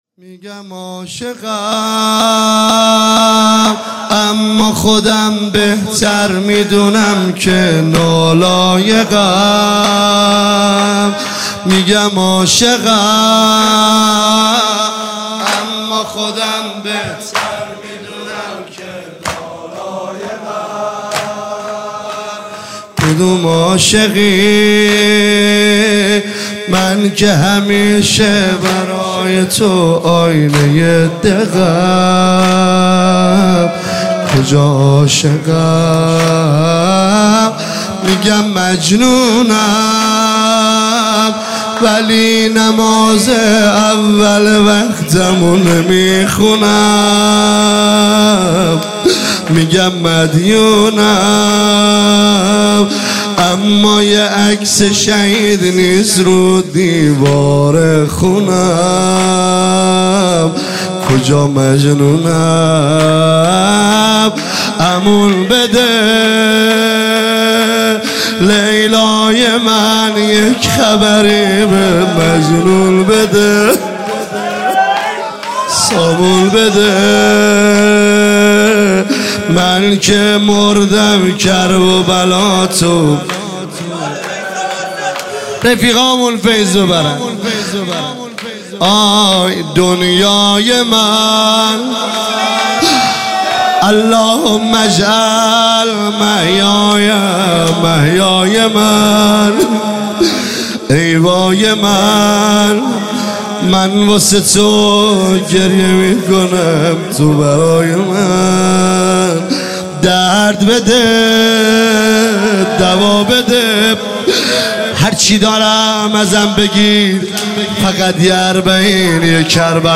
عنوان شب ششم ماه مبارک رمضان ۱۳۹۸
مداح
چهار ضرب پایانی